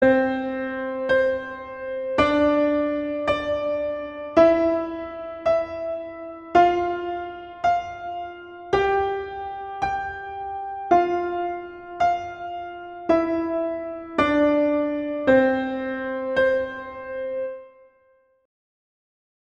classical, instructional, children